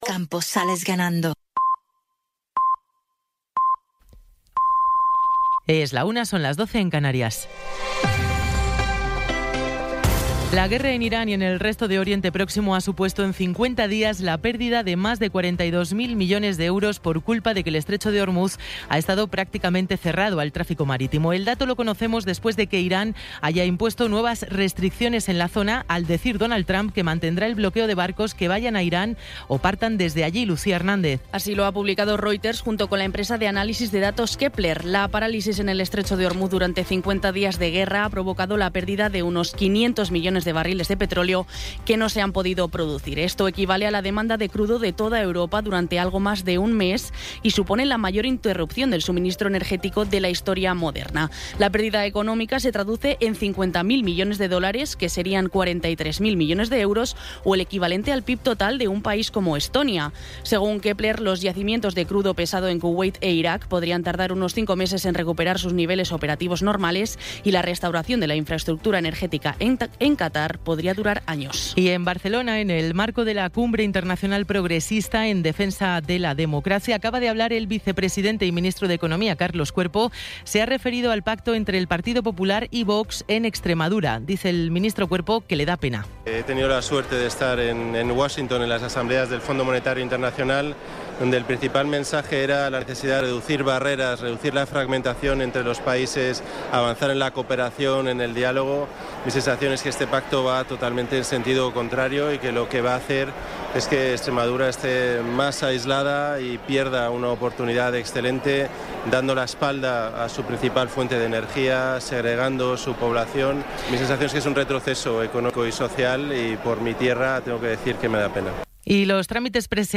Resumen informativo con las noticias más destacadas del 18 de abril de 2026 a la una de la tarde.